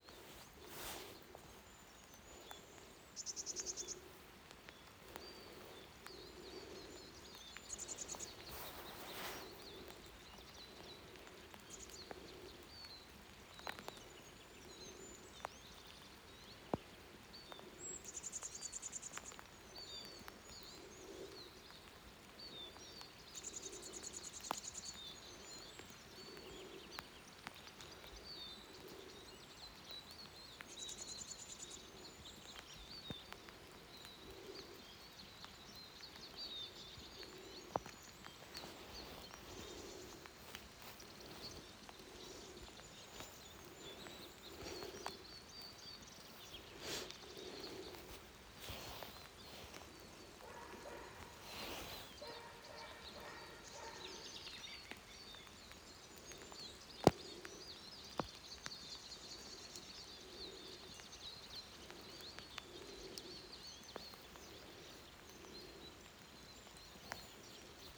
Art: Eurasian Siskin ( Spinus spinus ) Merknad: Vanskelig å høre hvor mange det er. I blandingsskog ved vann nær bebyggelse. Habitat: Mixed forest Aktivitet: Syngende Vedlegg Last ned